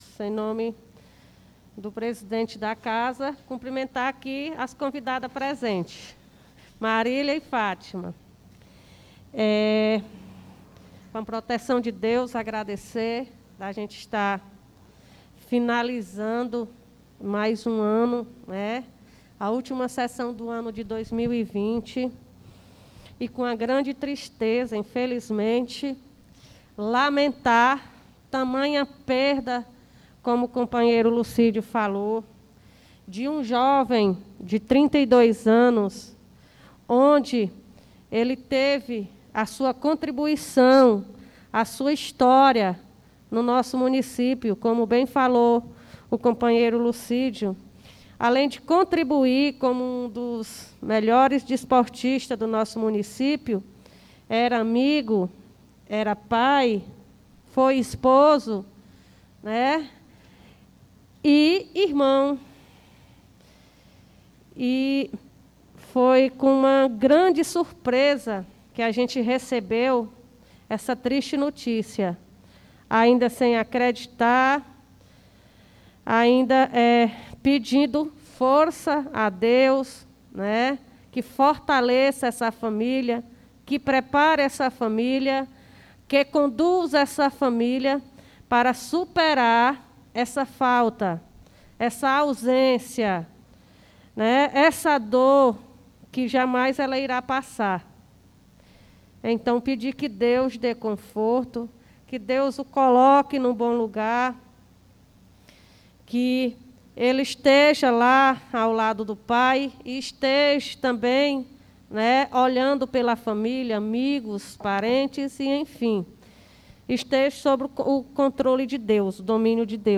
Pronunciamento Ver Márcia Macedo
2ª Sessão Ordinária